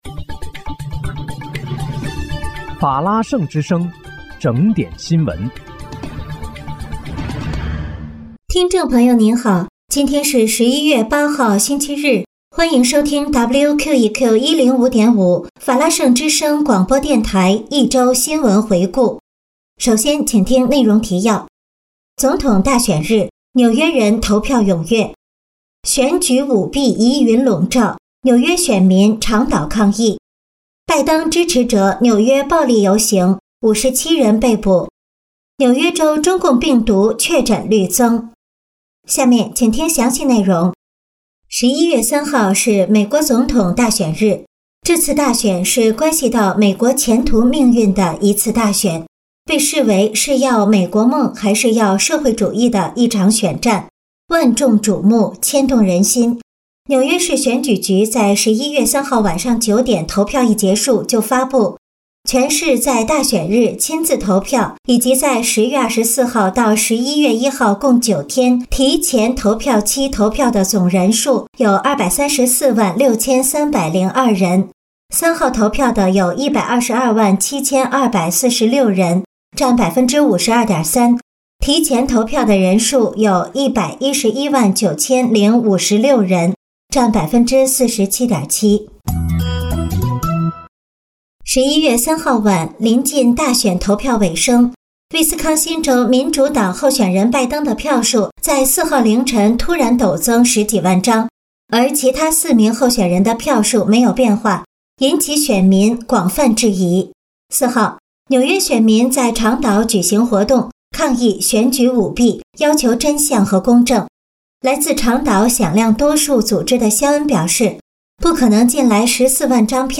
11月8日（星期日)一周新闻回顾